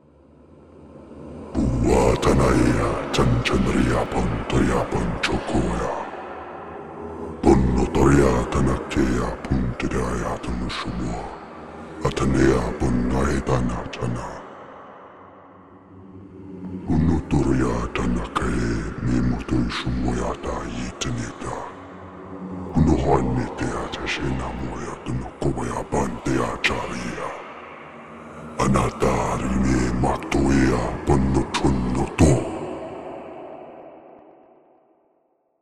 freesound_demon_chant(use_forcespeaker).mp3